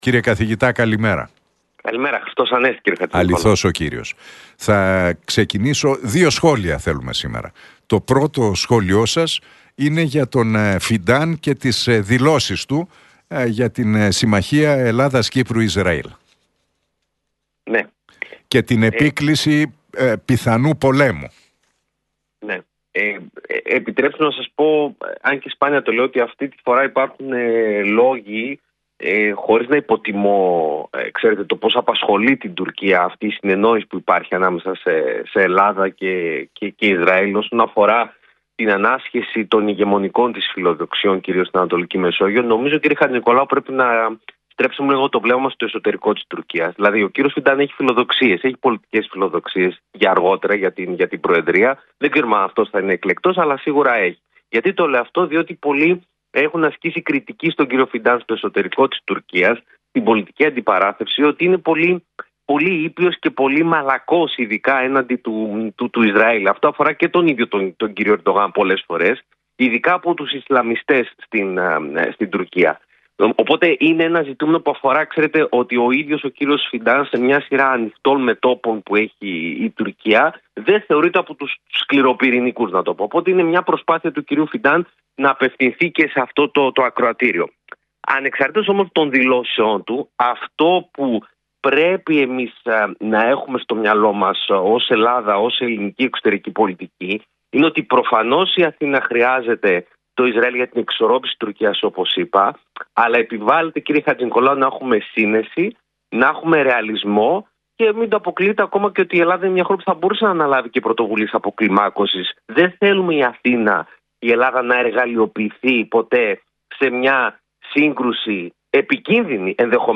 μίλησε στην εκπομπή του Νίκου Χατζηνικολάου στον Realfm 97,8.